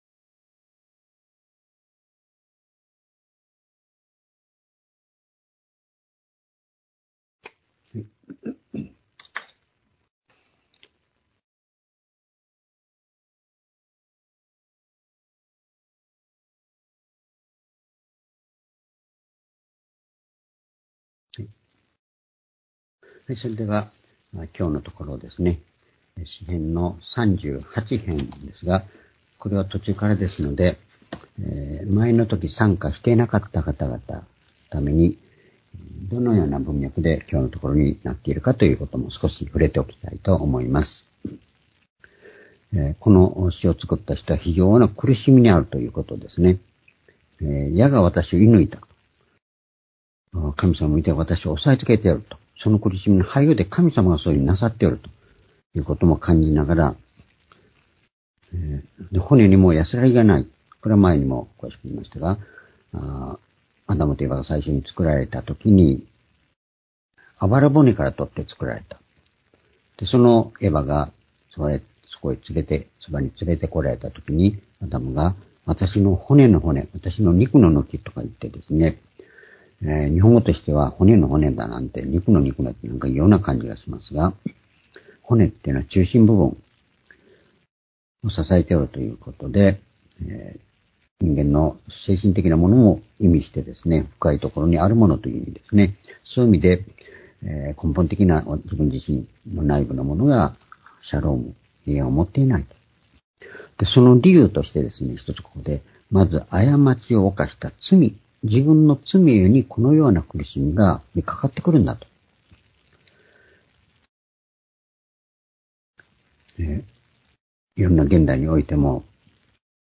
（主日・夕拝）礼拝日時 ２０２３年１１月２１日（火）夕拝 聖書講話箇所 「主よ、あなたを待ち望む」 詩篇38の14-23 ※視聴できない場合は をクリックしてください。